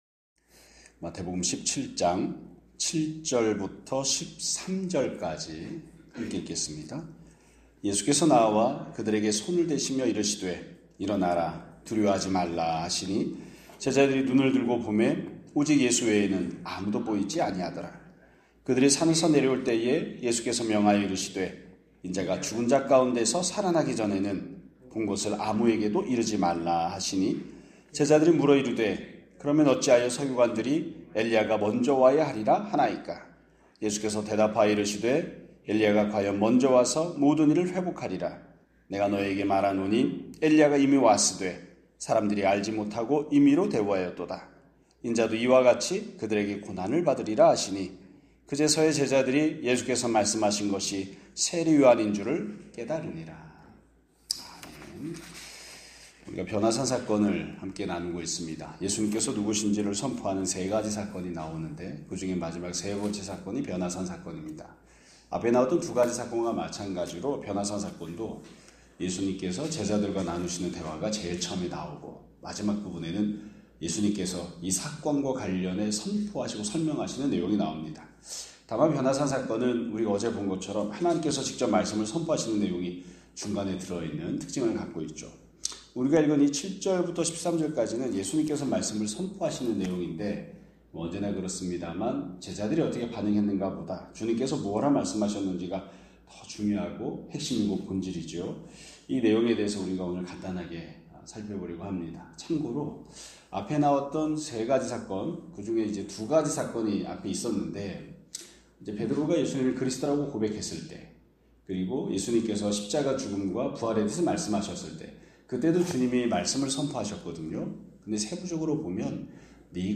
2025년 11월 25일 (화요일) <아침예배> 설교입니다.